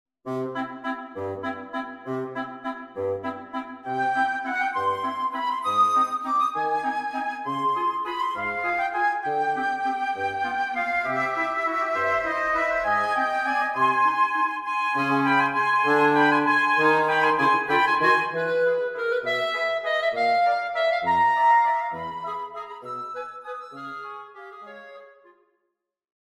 Flexible Woodwind Ensemble
Waltz excerpt (Flute, Oboe, Clarinet and Bassoon)